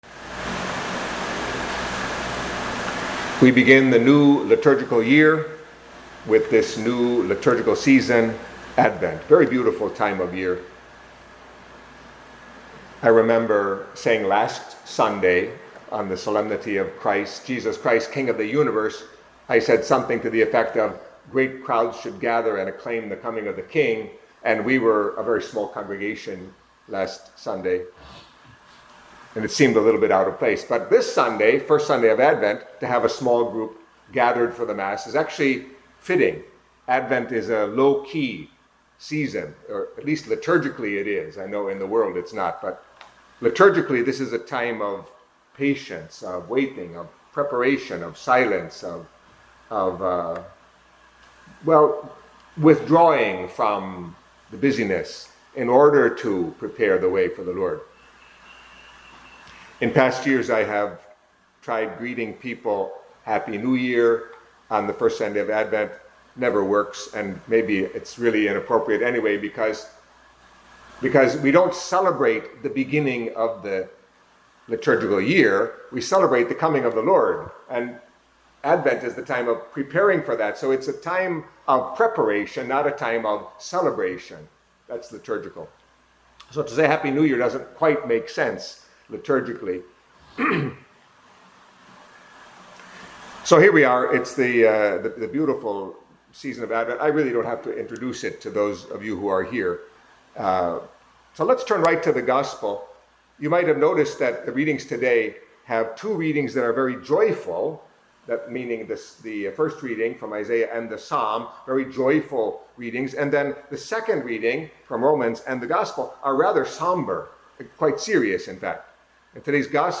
Catholic Mass homily for First Sunday of Advent